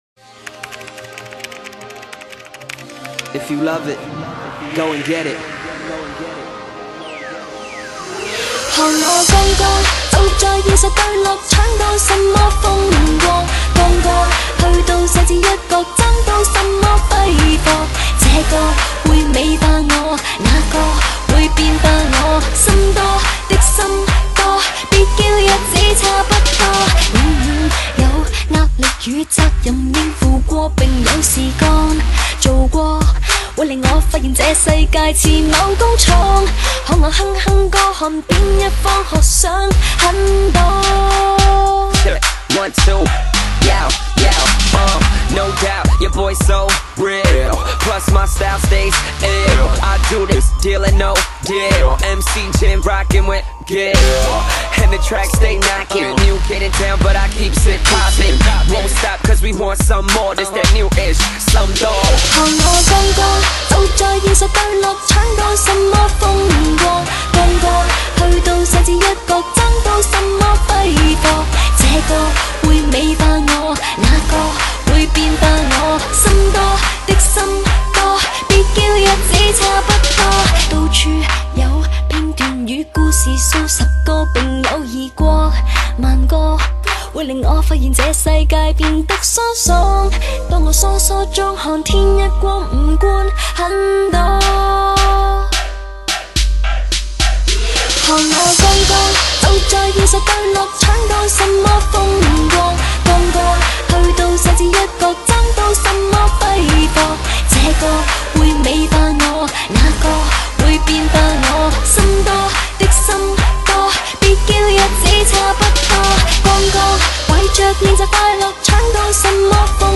风格: 流行